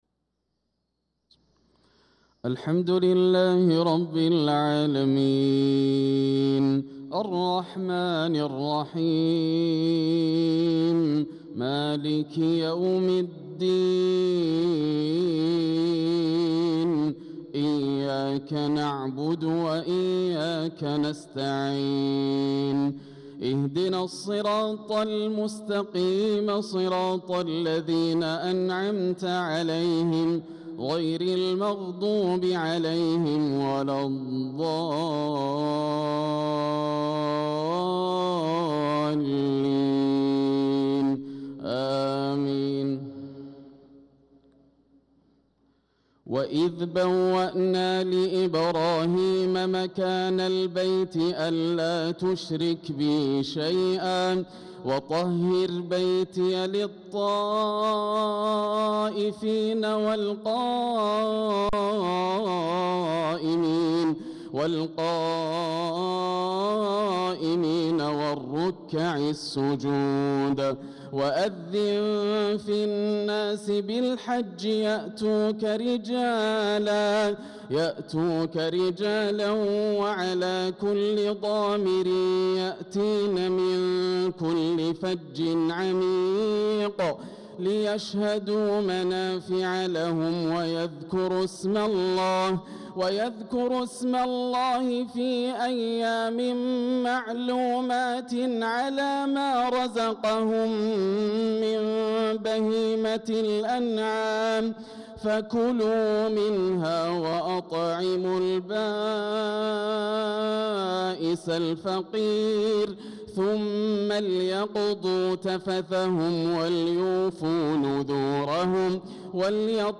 صلاة الفجر للقارئ ياسر الدوسري 8 ذو الحجة 1445 هـ
تِلَاوَات الْحَرَمَيْن .